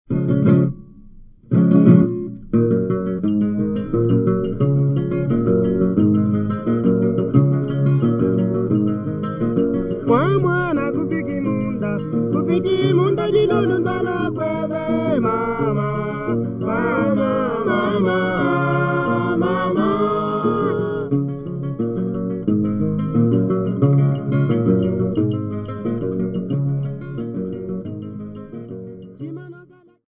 Folk music--Africa
Field recordings
sound recording-musical
Indigenous topical folk song with singing accompanied by 2 guitars and the sound of a struck bottle.